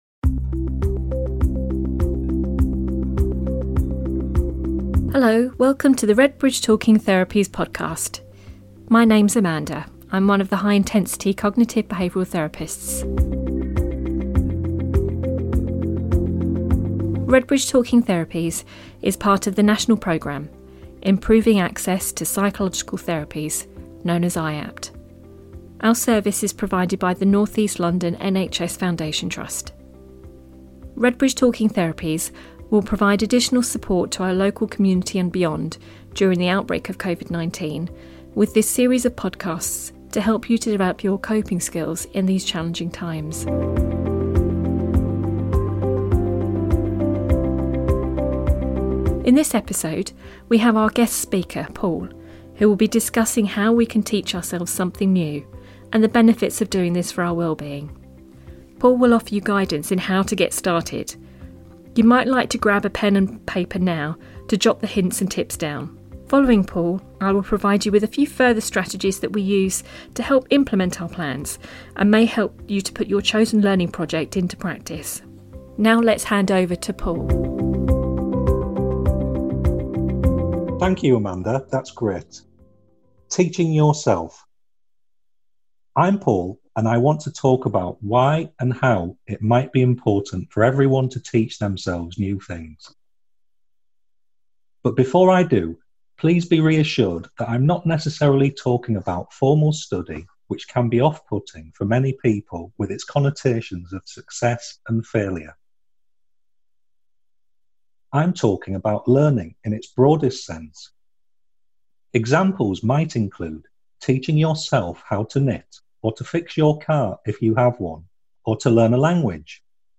podcast which I and a colleague gave on this subject.